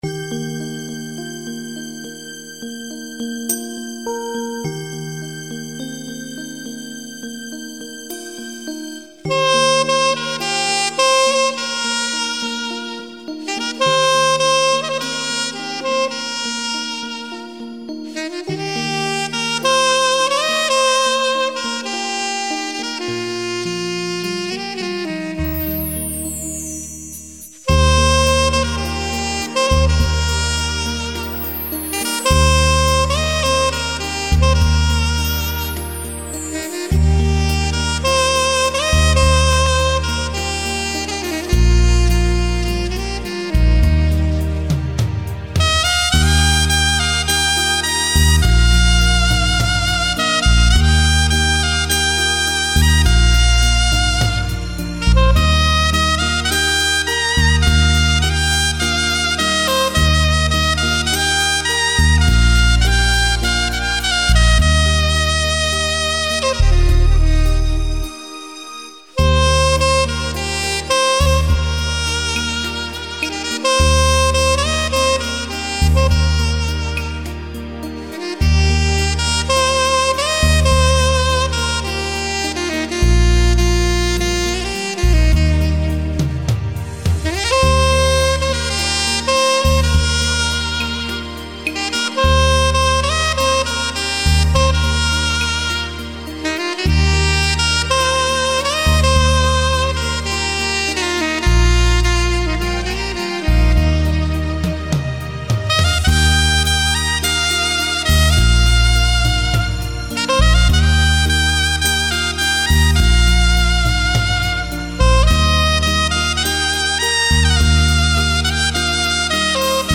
全身自然放松下来
静听自然之音